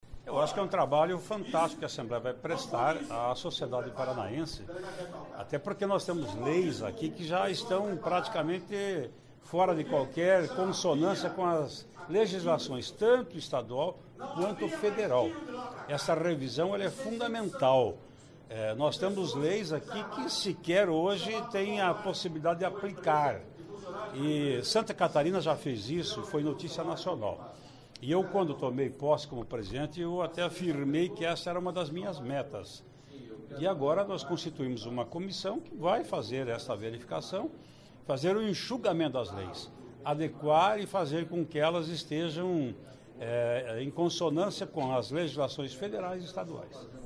Ouça entrevista com o presidente sobre a Comissão formada ano passado e que vai revisar todas as leis do Paraná.